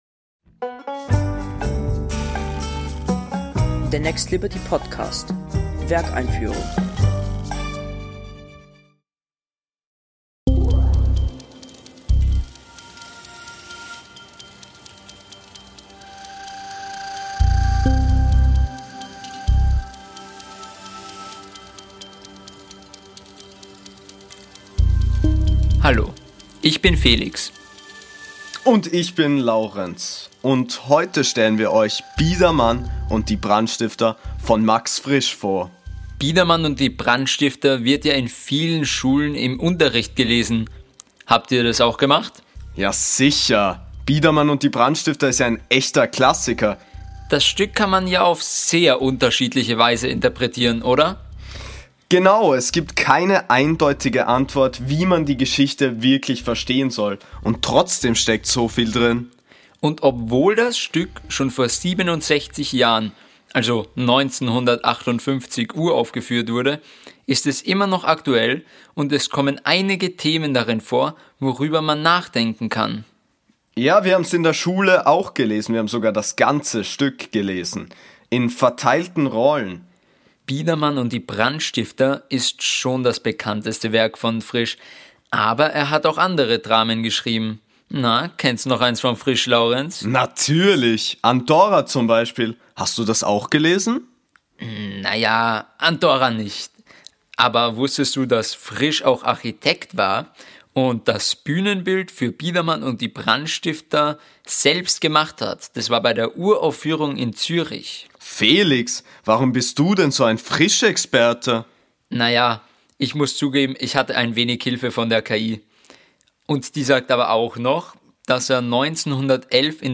Das und mehr erfahrt ihr in unserer Audio-Einführung, moderiert von zwei Spielclubteilnehmern, mit O-Tönen aus der Inszenierung, Statements des Leading Teams und allen wichtigen Infos zum Stück „to go“.